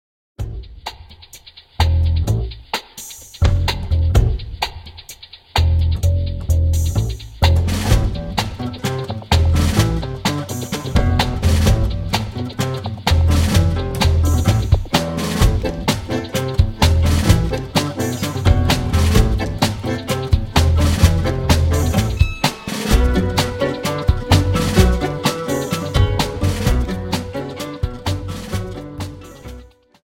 Dance: Tango 32